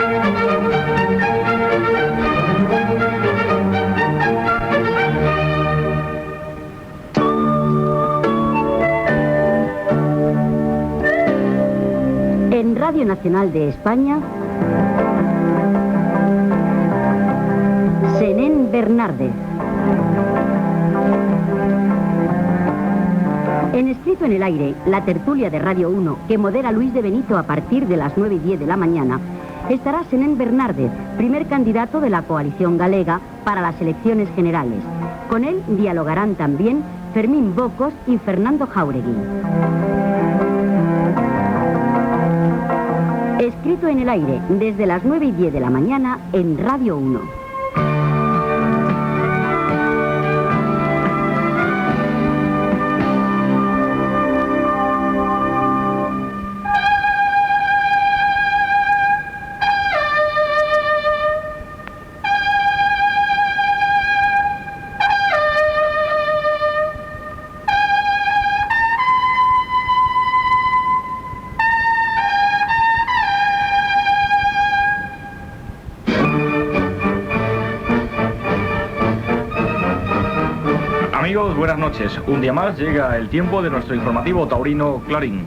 Sintonia de l'emissora, promoció del programa "Escrito en el aire", sintonia del programa i presentació
Informatiu